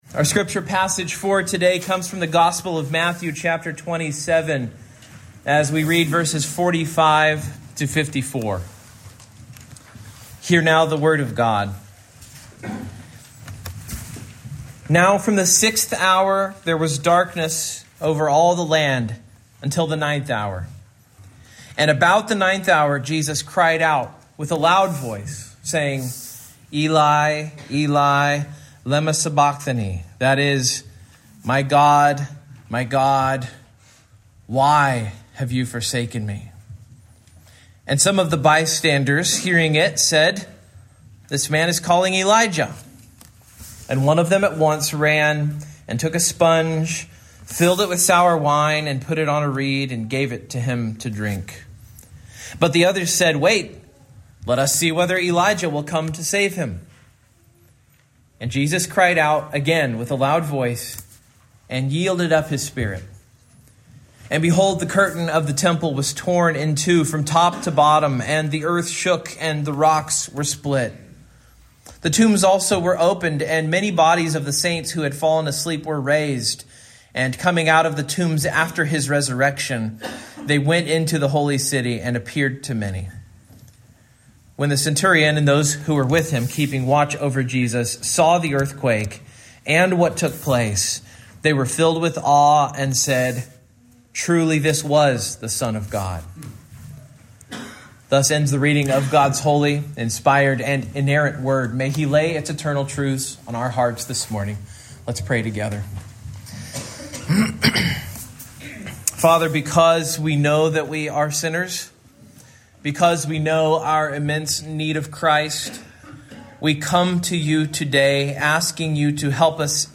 Matthew 27:45-54 Service Type: Morning Main Point